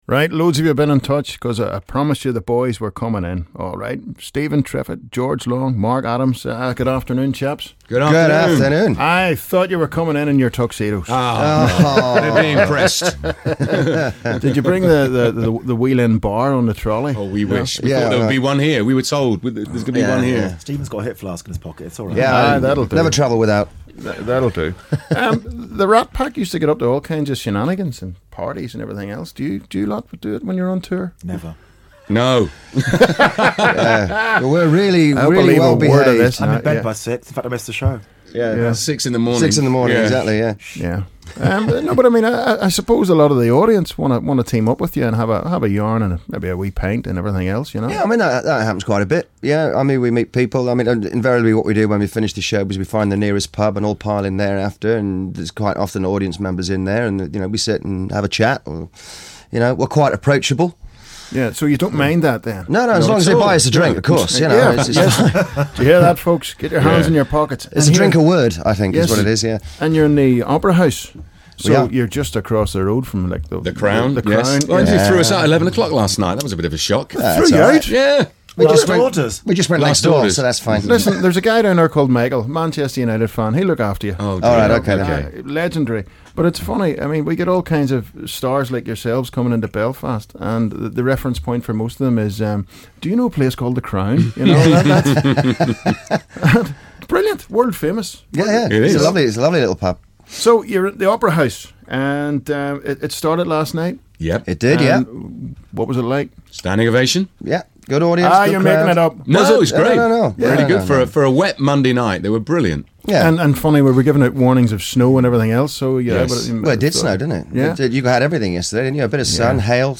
The Rat Pack Interview